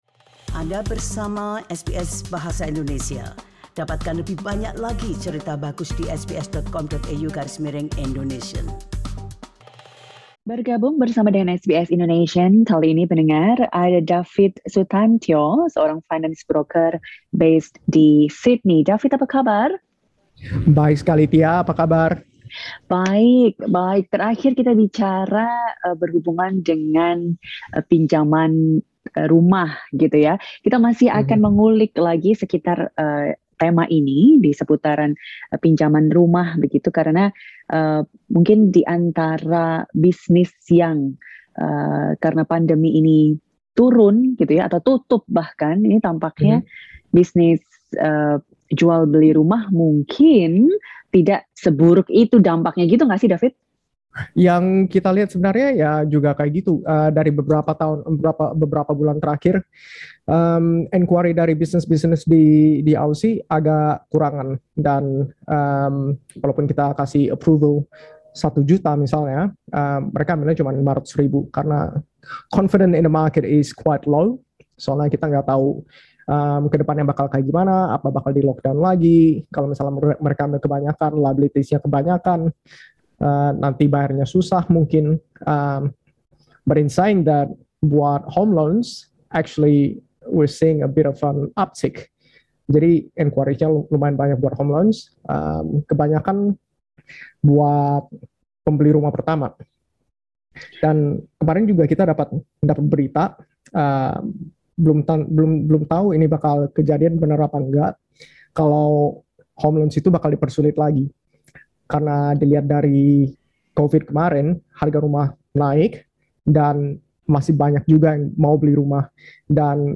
Berikut ini cuplikan wawancaranya.